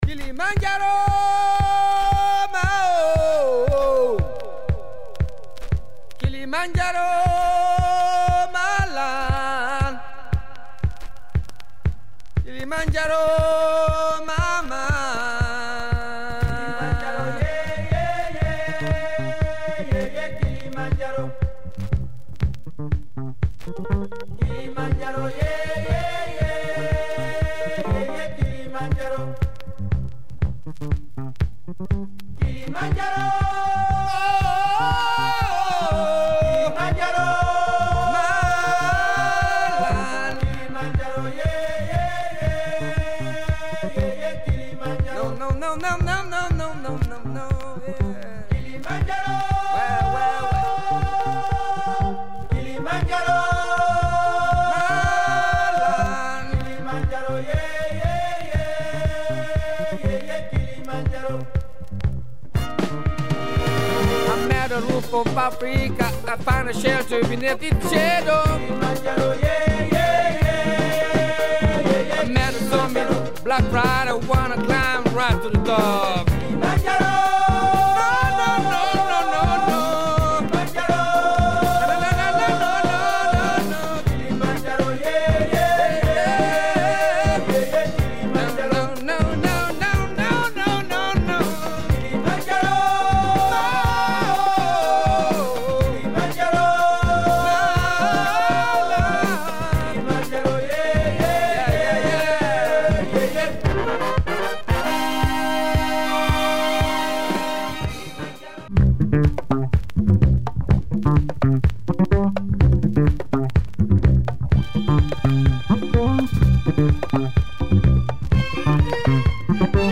Famous afro funk album